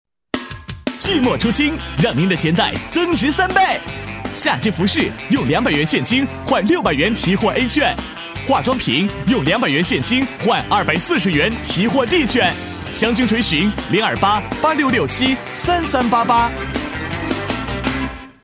8 男国94_广告_促销_服装促销_年轻 男国94
男国94_广告_促销_服装促销_年轻.mp3